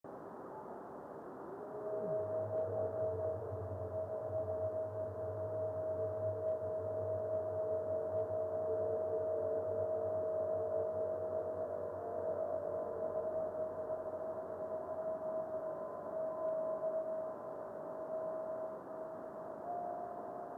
dual forward scatter system;   video and stereo sound:
Meteor reflection occurs during the 0436 UT minute.